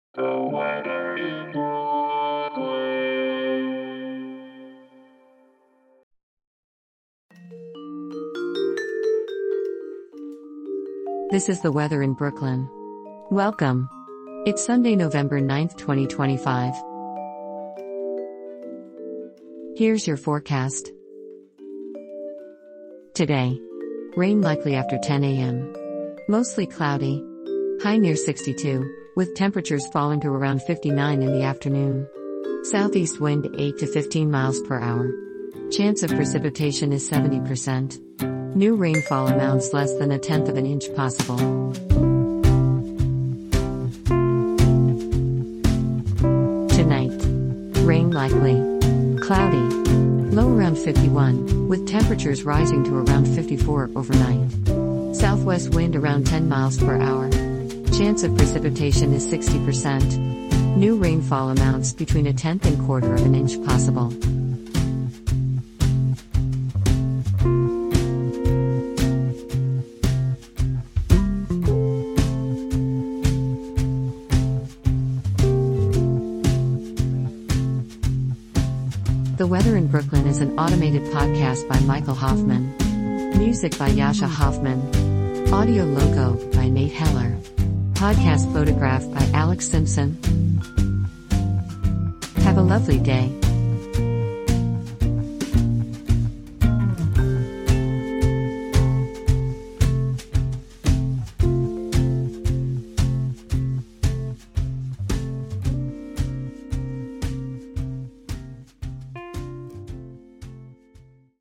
Weather forecast courtesy of the National Weather Service.